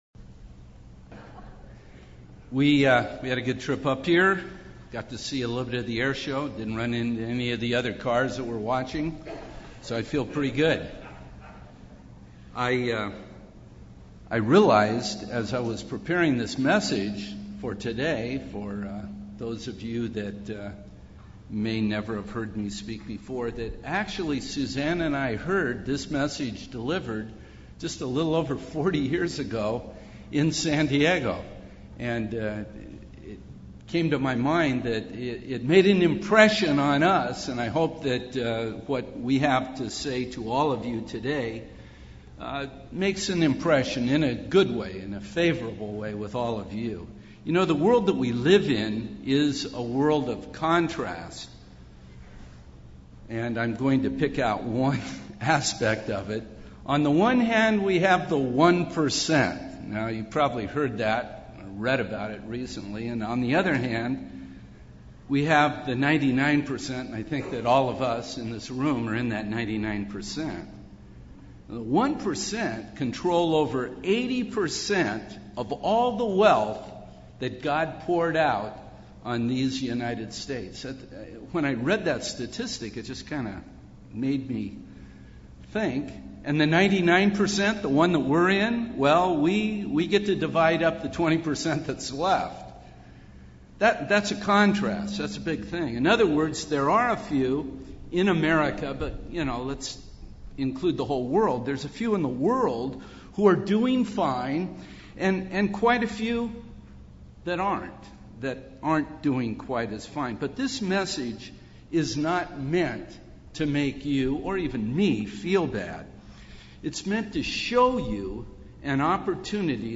The Gospel of the Kingdom of God can give purpose to our lives. This seminar message provides general principles about the Kingdom of Heaven, also known as the Kingdom of God that was preached by Jesus Christ and the apostles.